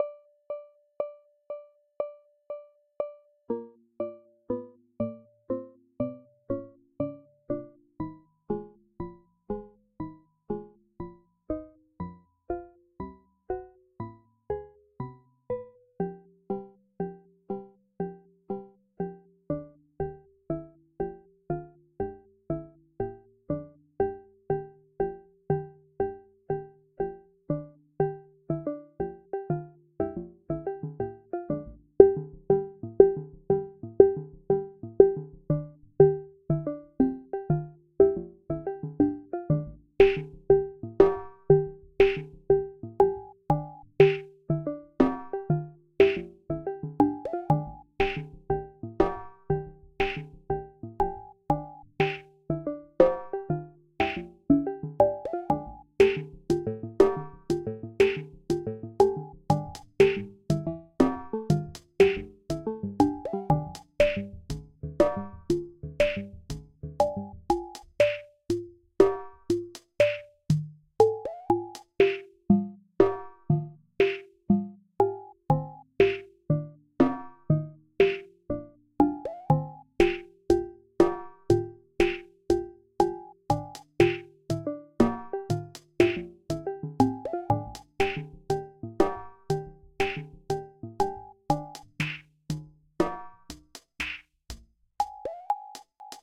Música electrónica
melodía
sintetizador